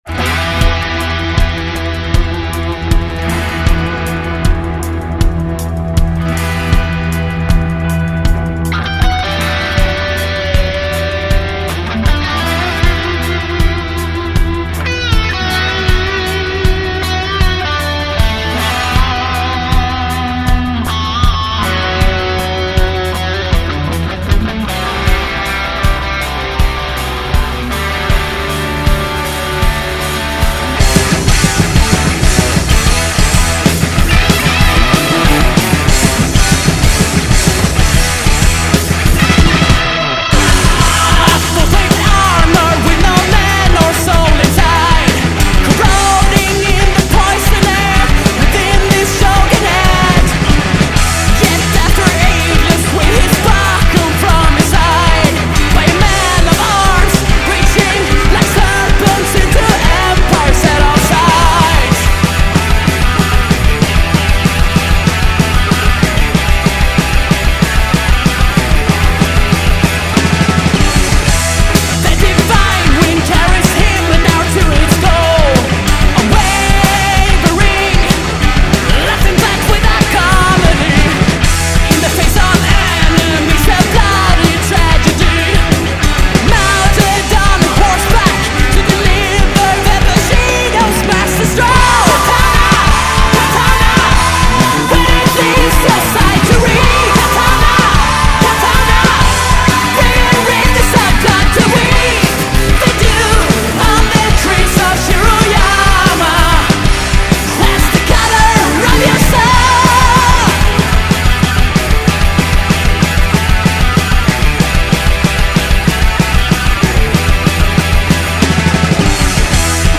a fine example of classic quality Heavy Metal done right.
Swedish classic-metal act